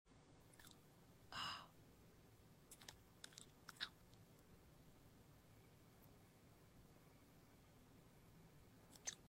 Star Fruit Eating ASMR Sound Effects Free Download